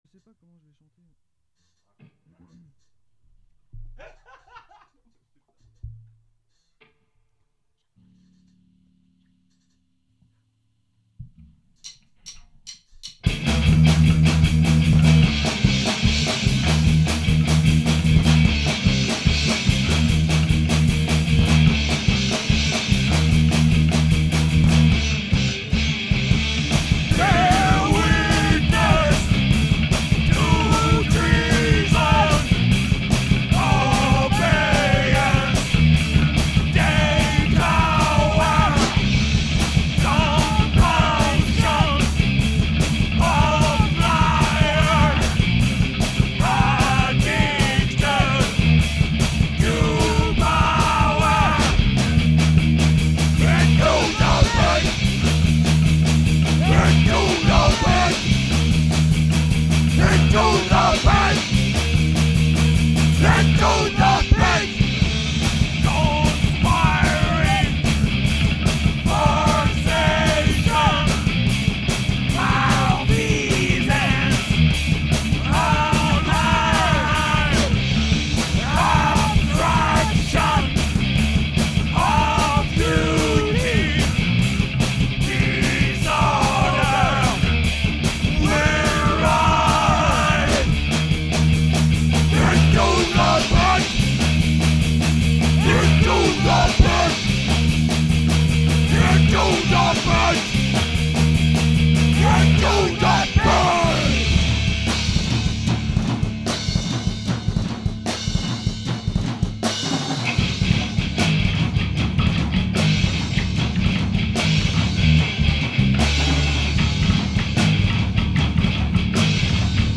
1er mixage avec les voix
Un peu de douceur qui fait mal aux tympans